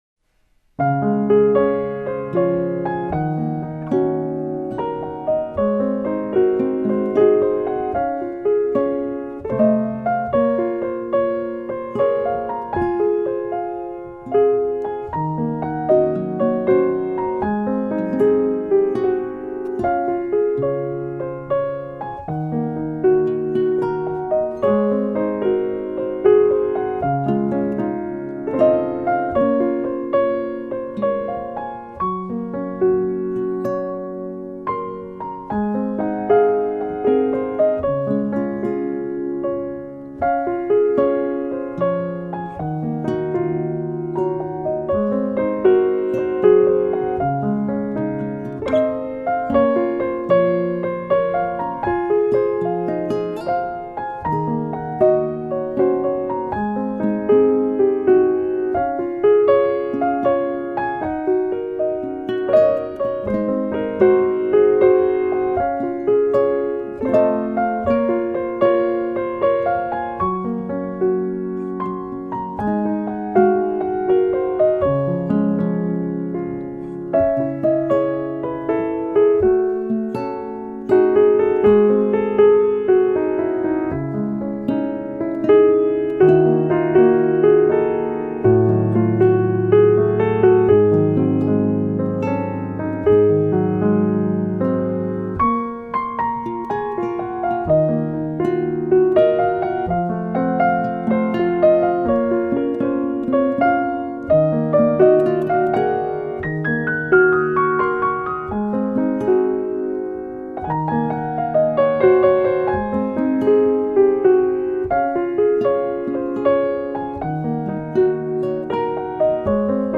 鋼琴
部分曲子加入弦樂、吉他、手風琴等樂器，呈現更豐富的音樂氛圍。
用最溫柔、平和的曲調表現出來。